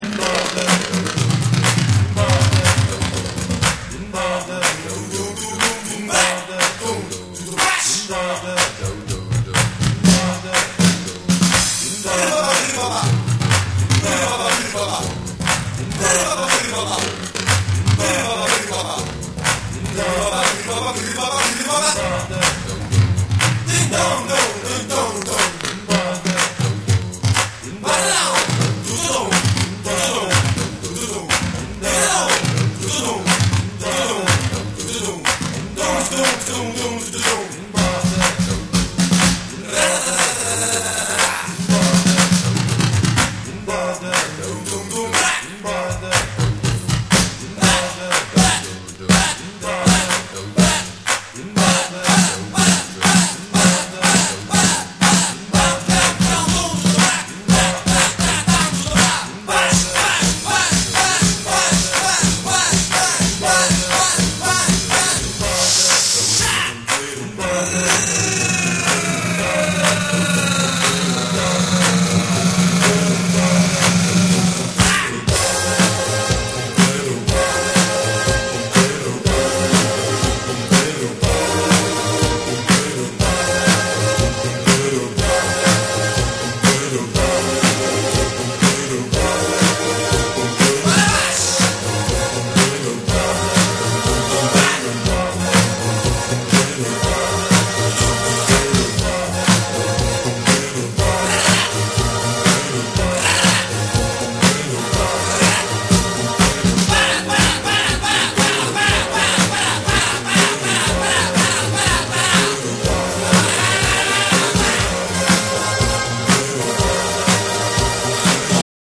トライバルなビートに呪術的ともいえるスキャットが絡みスペイシーなシンセが展開する強烈ナンバー！
DANCE CLASSICS / DISCO